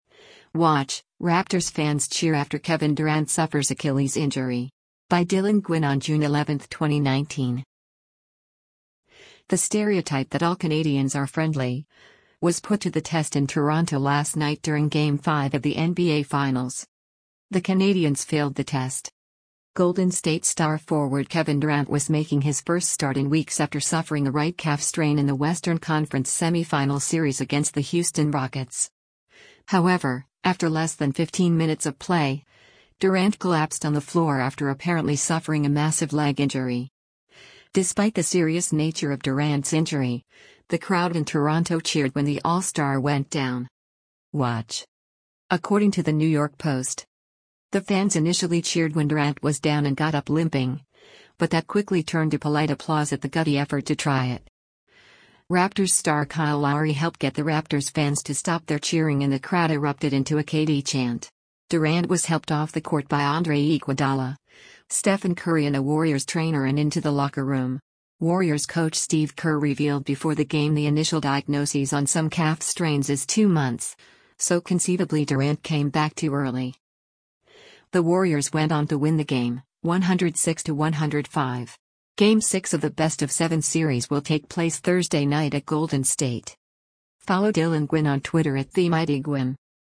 WATCH: Raptors Fans Cheer After Kevin Durant Suffers Achilles Injury
The stereotype that all Canadians are friendly, was put to the test in Toronto last night during Game 5 of the NBA Finals.
Despite the serious nature of Durant’s injury, the crowd in Toronto cheered when the All-Star went down.
The fans initially cheered when Durant was down and got up limping, but that quickly turned to polite applause at the gutty effort to try it.